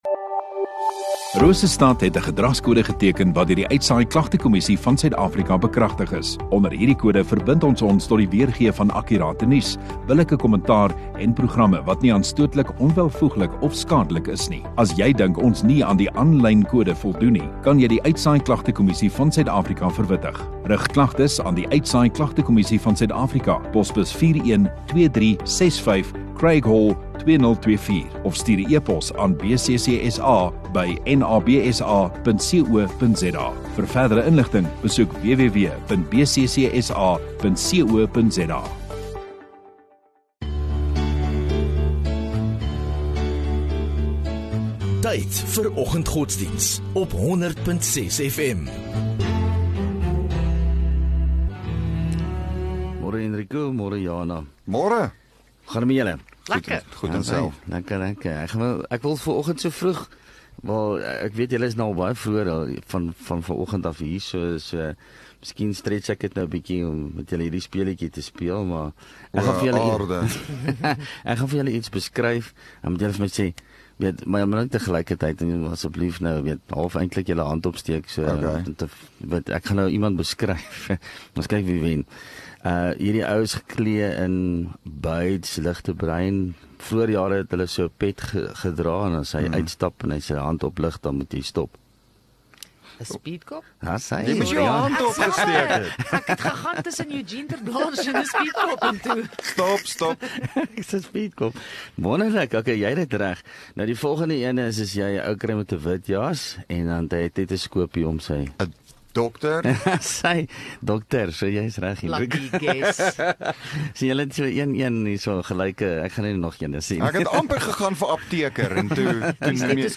Godsdiens